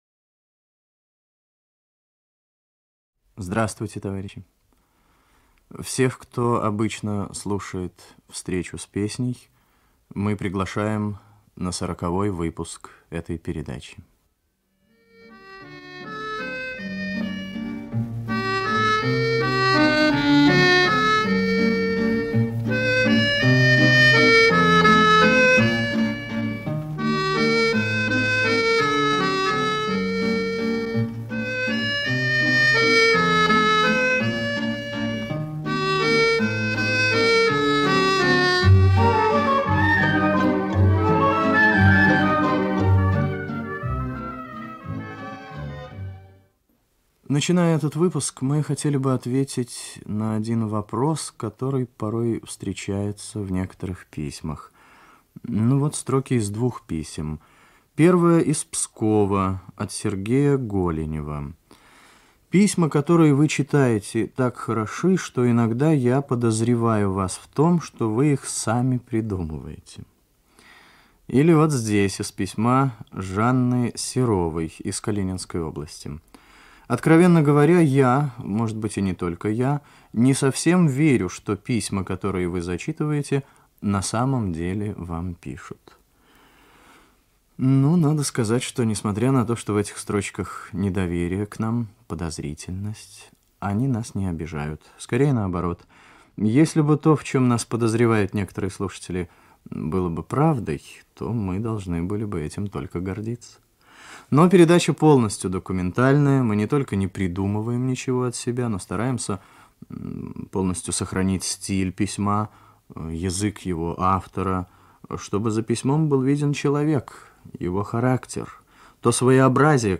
Русская народная песня